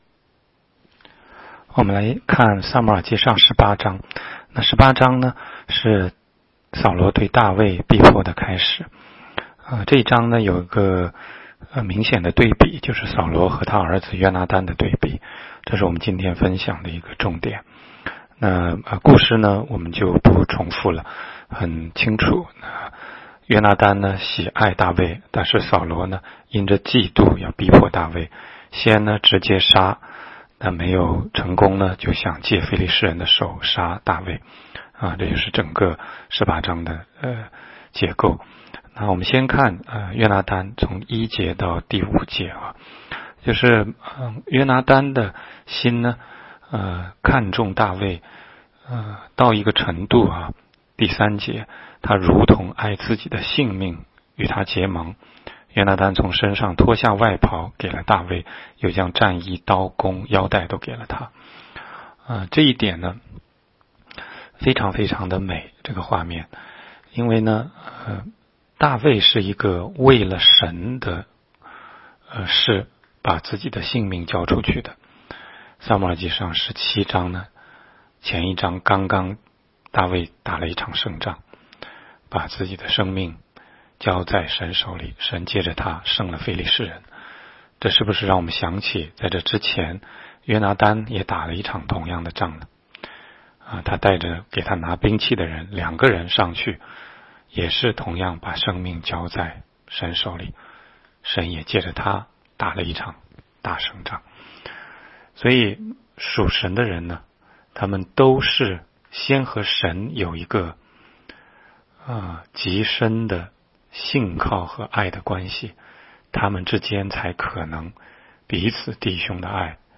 16街讲道录音 - 每日读经-《撒母耳记上》18章